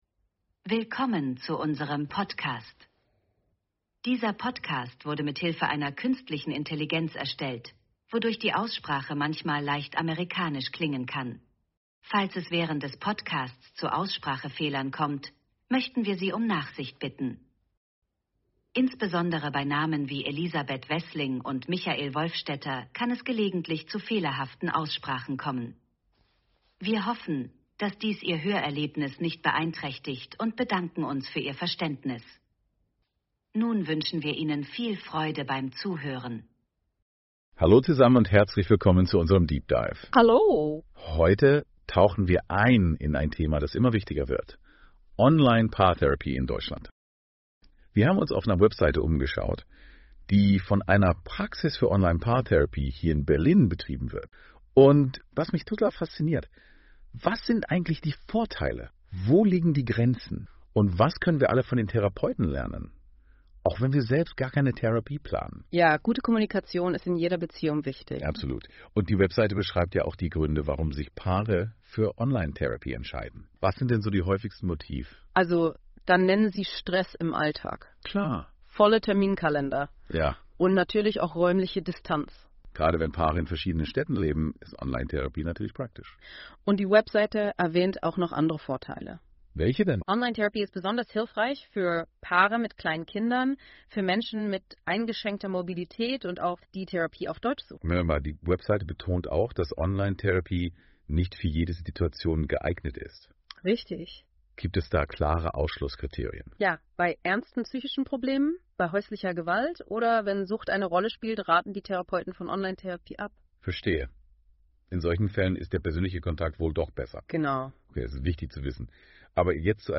Der Podcast wurde mit KI erstellt, um eine hochwertige und verständliche Wiedergabe zu bieten.